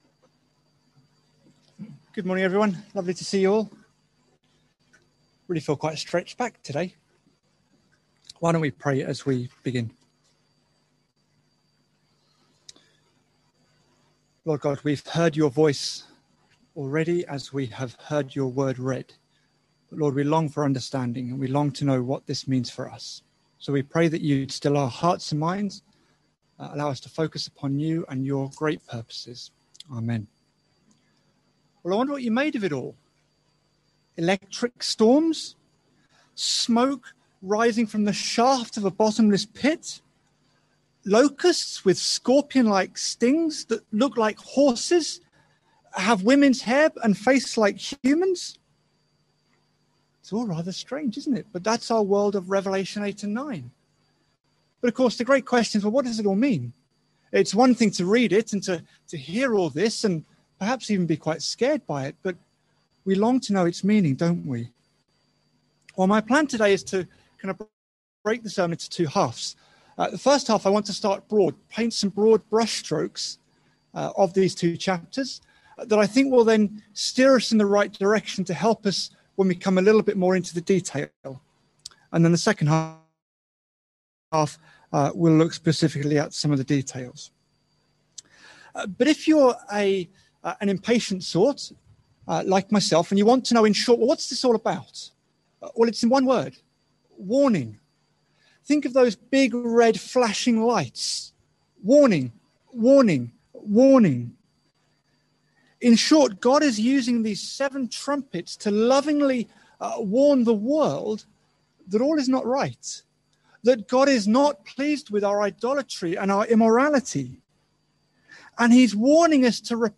Single Sermon | Hope Church Goldington
Sunday Sermon 6th June 2021.mp3